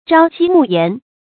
朝齏暮鹽 注音： ㄓㄠ ㄐㄧ ㄇㄨˋ ㄧㄢˊ 讀音讀法： 意思解釋： 齏：腌菜。